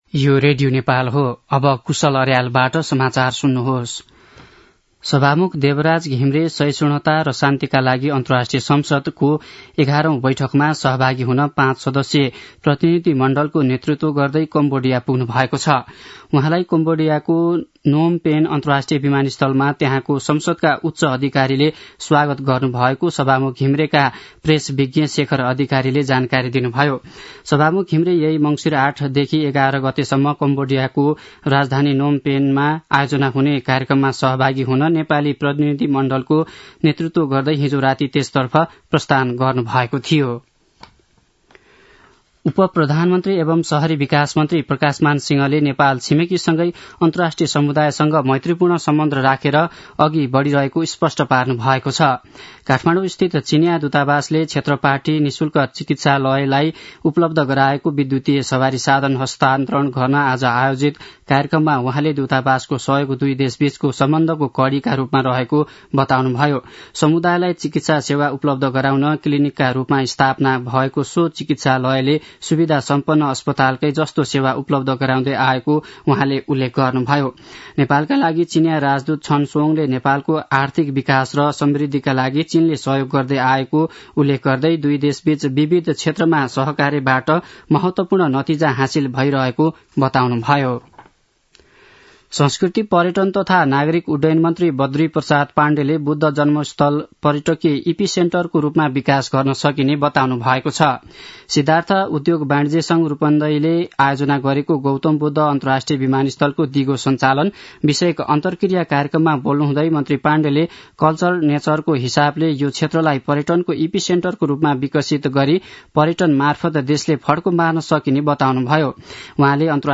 दिउँसो १ बजेको नेपाली समाचार : ९ मंसिर , २०८१
1-pm-nepali-news.mp3